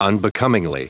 Prononciation du mot unbecomingly en anglais (fichier audio)
unbecomingly.wav